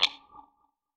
Tap.wav